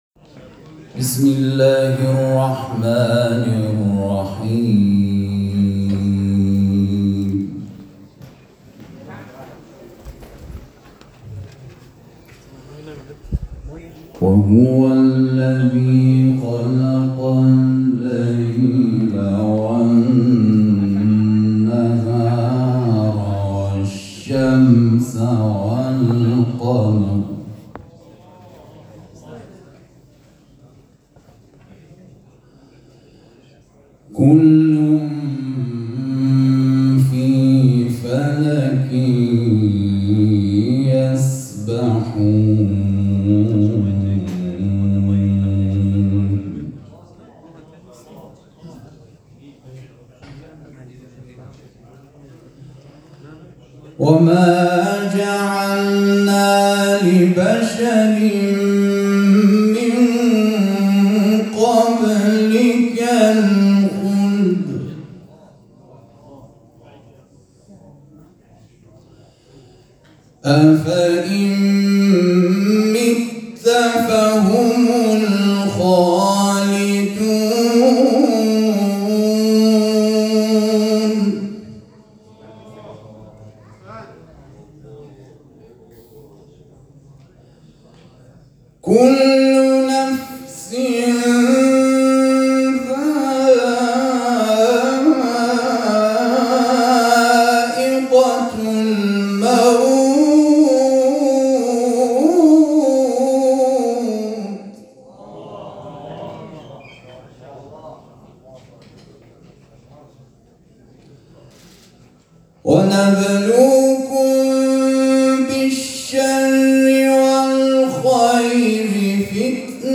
چهل و پنجمین دوره مسابقات قرآن